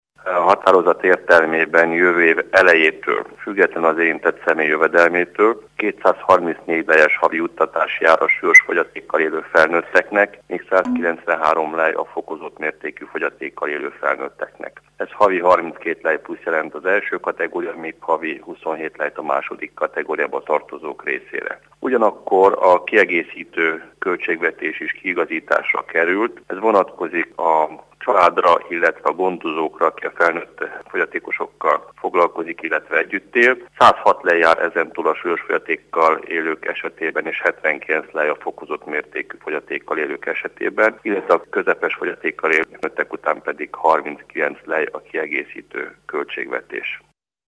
Hallgassa meg Horváth Levente nyilatkozatát!
horvath_levente_fogyatekosok.mp3